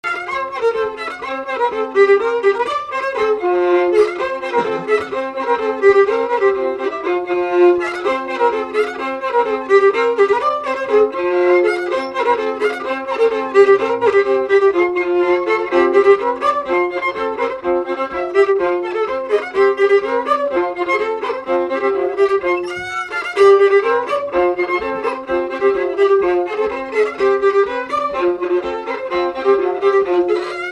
Séga
Salazie
Instrumental
danse : séga
Pièce musicale inédite